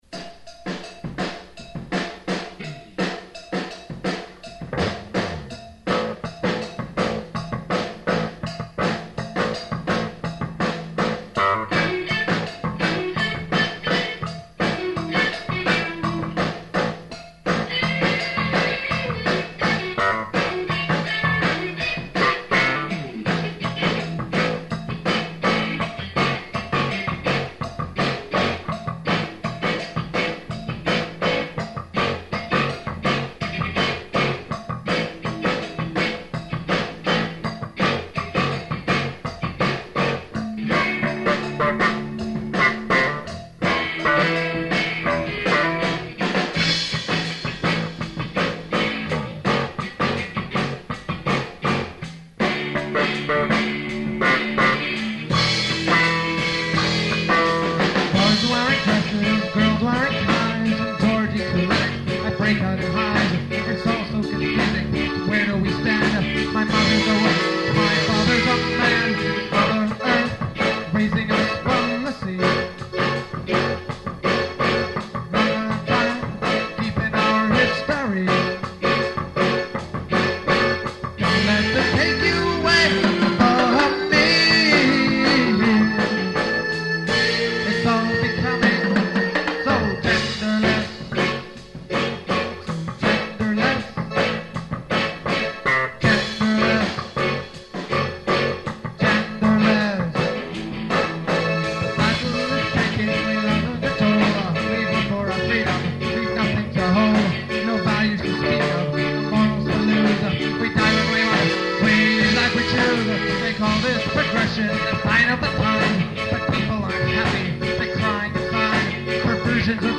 Keep in mind that these are from 20-year old cassette tapes, so sound quailty is definitely an issue. We apologize in advance for all the mistakes and questionable singing, but this was a practice session after all.
The band played some covers and mostly original material in the style of ska and reggae.